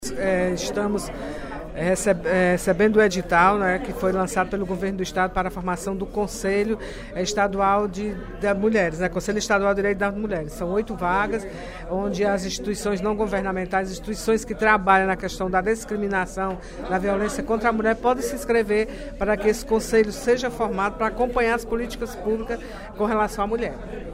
No primeiro expediente da sessão plenária desta quinta-feira (27/02), a deputada Mirian Sobreira (Pros) ressaltou a importância dos conselhos, entidades, delegacias e demais políticas públicas voltadas para as mulheres.